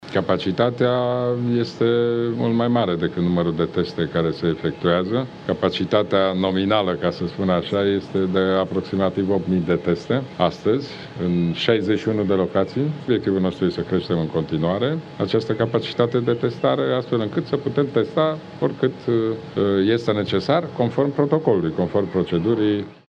Aflat joi dimineață în vizită la Institutul de Cercetare „Cantacuzino”din Bucuresti, premierul Ludovic Orban anunță că acolo vor putea fi făcute în curând, o mie de teste pe zi pentru coronavirus, practic, o dublare a capacitatii de testare.